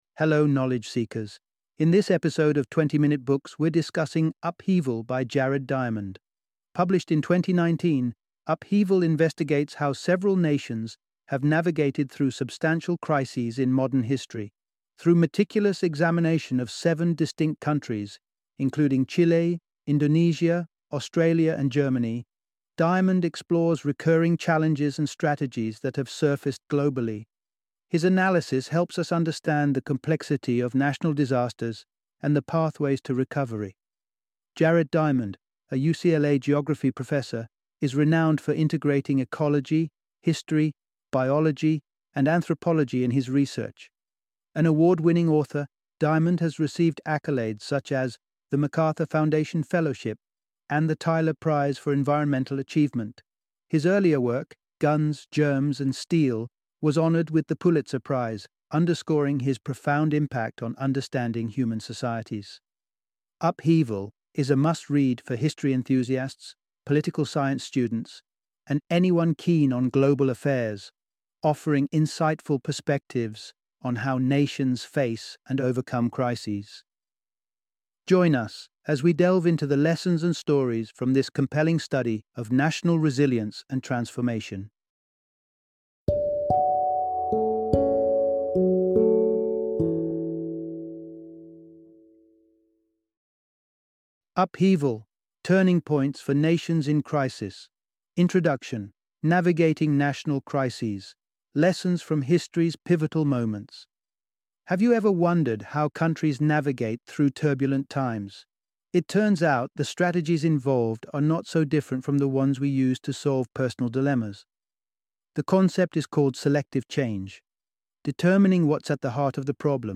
Upheaval - Audiobook Summary